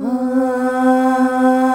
AAAAH   C.wav